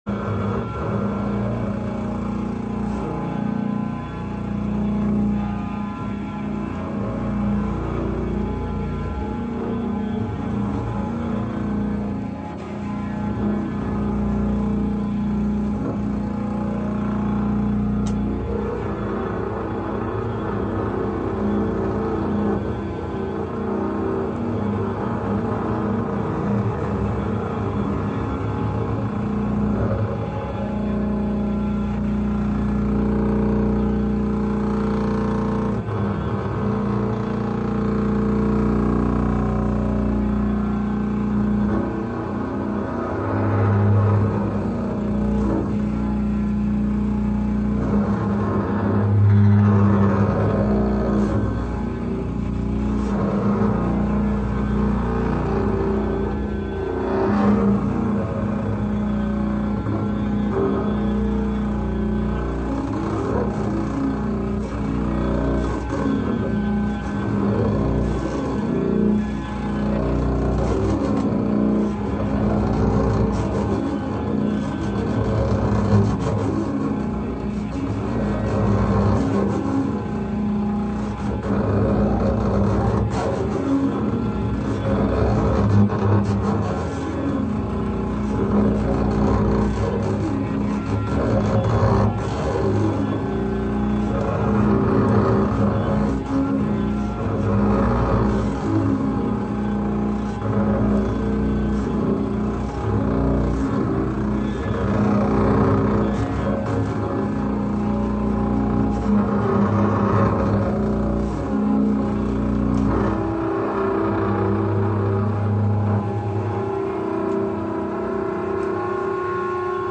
a flute and feedback duo